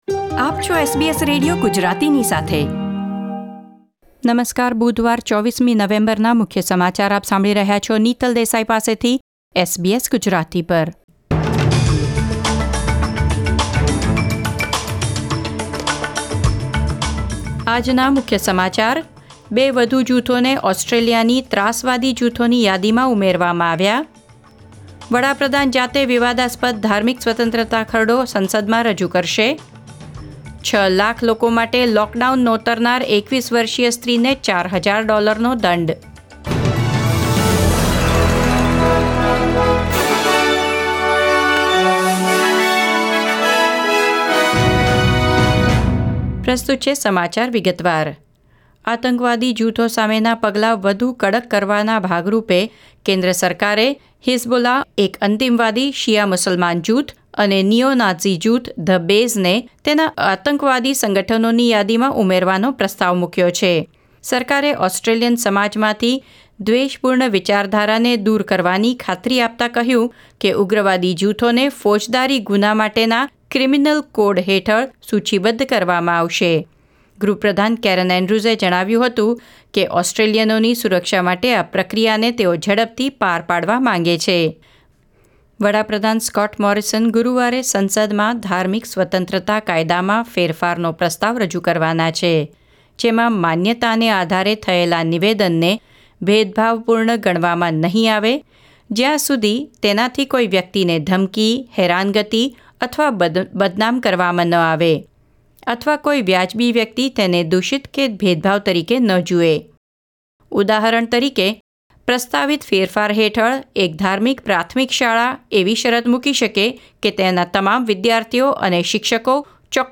SBS Gujarati News Bulletin 24 November 2021